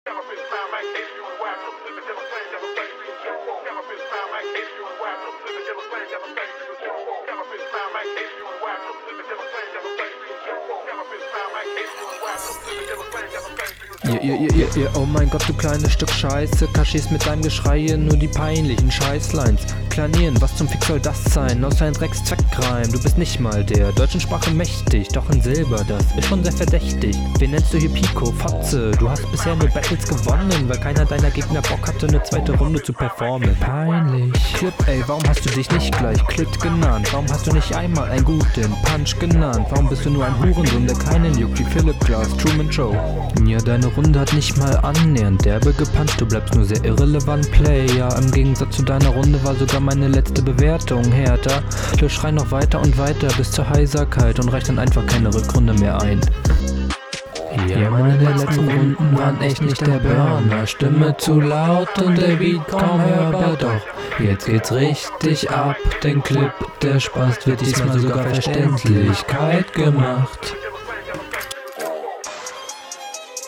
Tut mir leid aber das ist leider immer noch konstant am Takt vorbei.